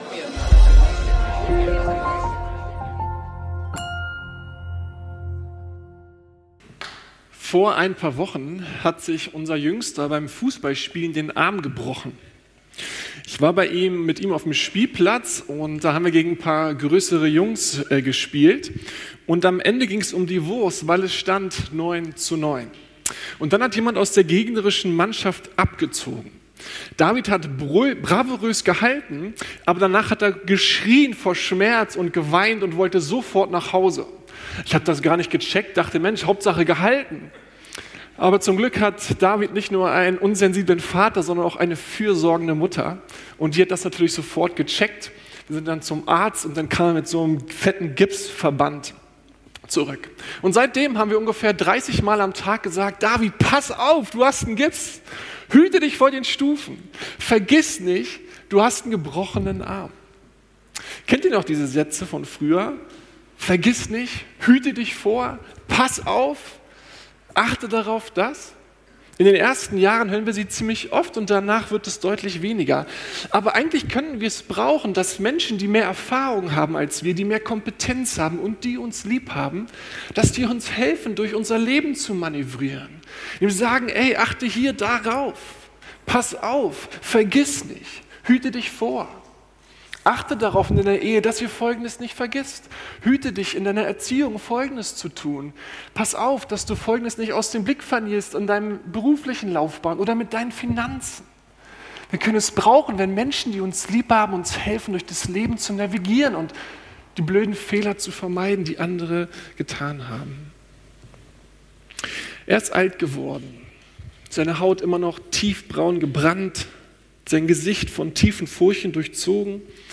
Bundeserneuerung ~ Predigten der LUKAS GEMEINDE Podcast